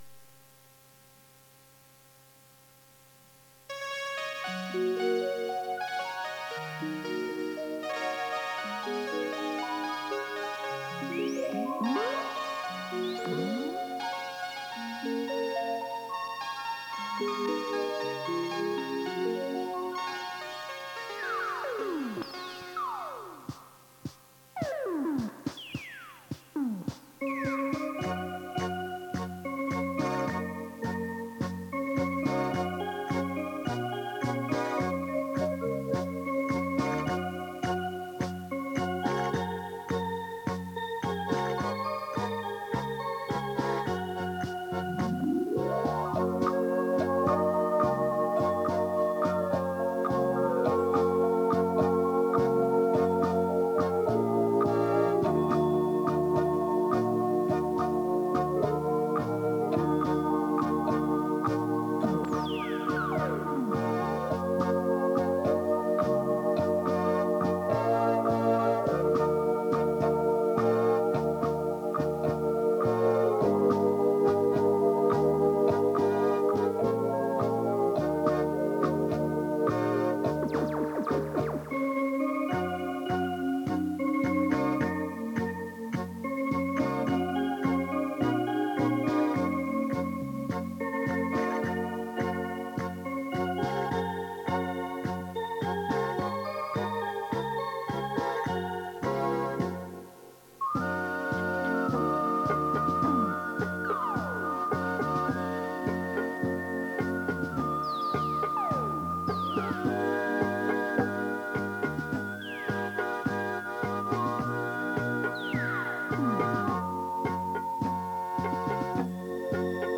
磁带数字化：2022-06-04